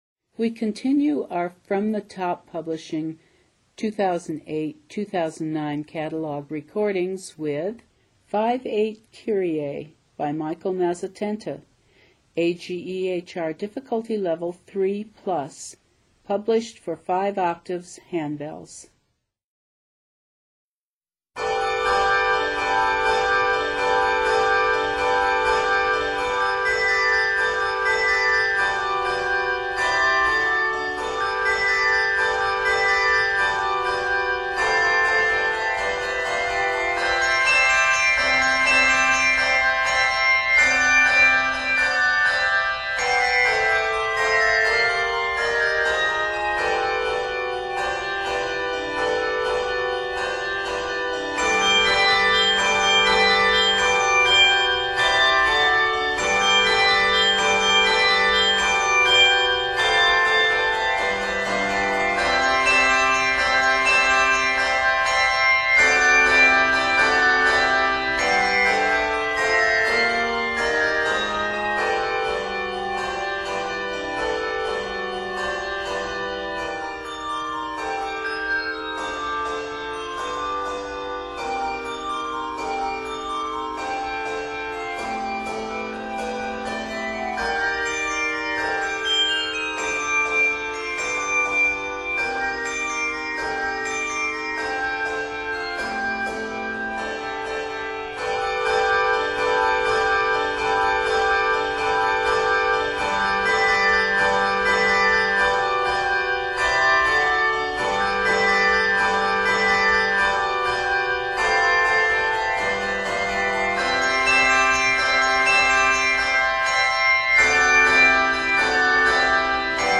is written in G Major and is 116 measures.